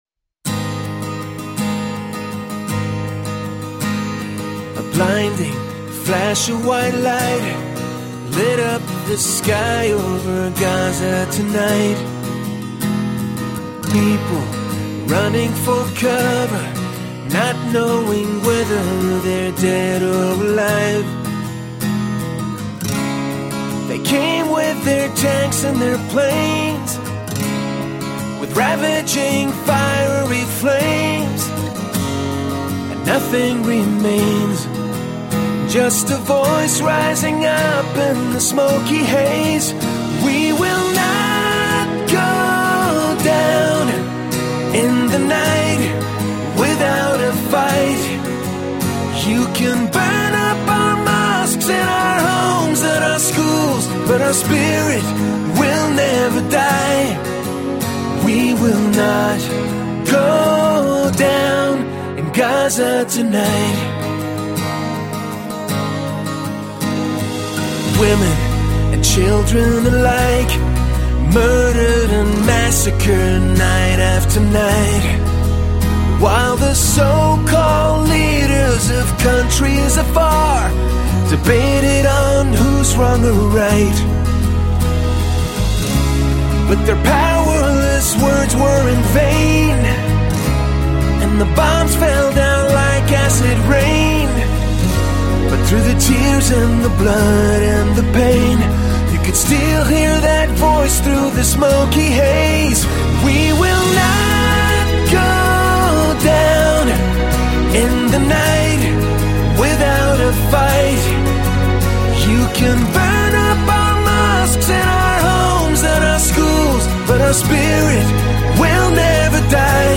آهنگ زیبا